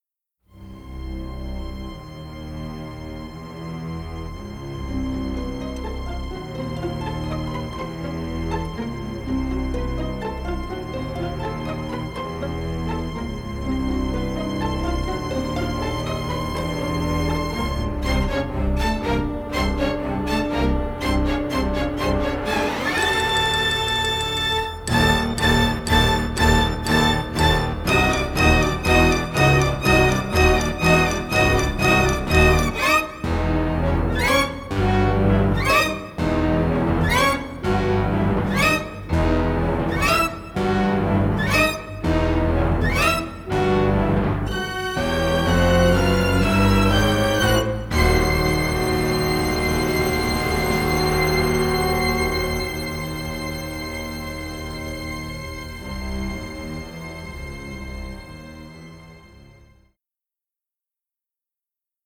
Dynamic orchestral score